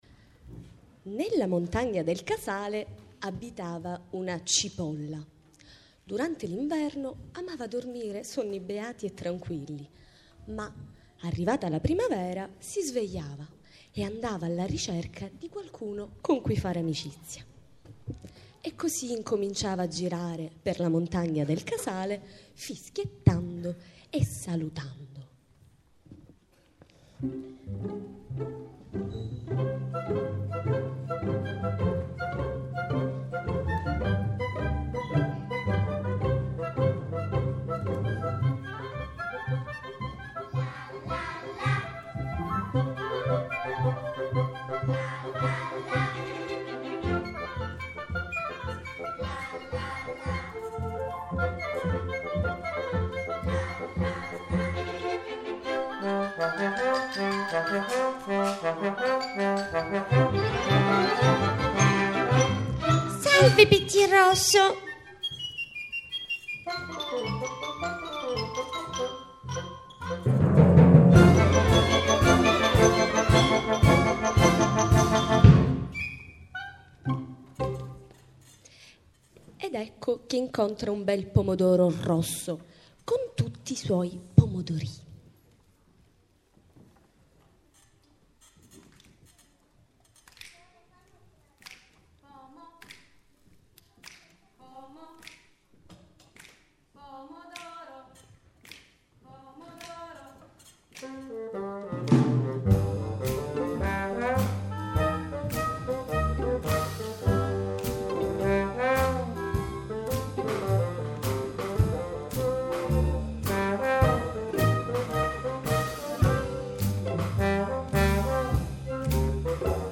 Sono inclusi rumori di fondo e piccoli difetti musicali ma, sicuramente questo ti far� sentire l’emozione che abbiamo sentito noi nell’eseguirla.
la storia della cipolla del casale live.mp3